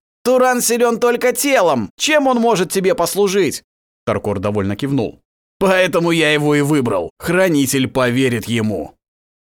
AudioBook
Муж, Аудиокнига/Средний
Профессиональная студия звукозаписи со всем сопутствующим оборудованием.